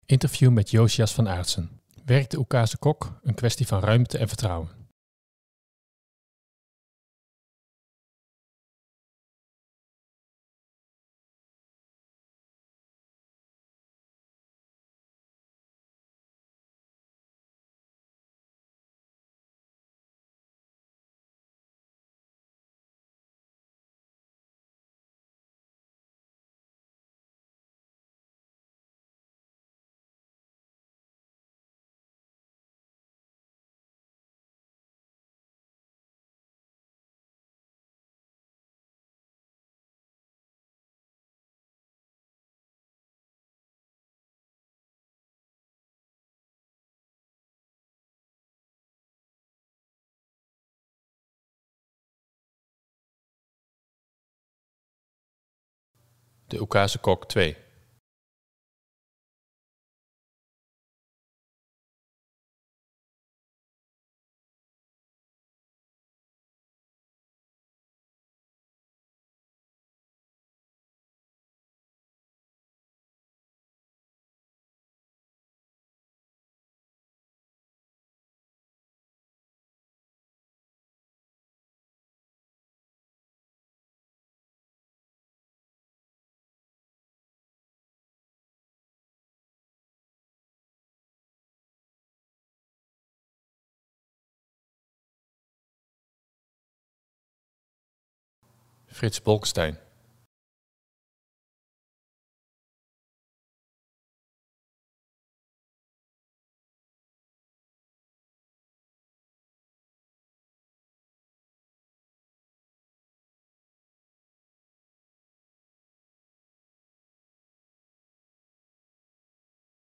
Interview met Jozias van Aartsen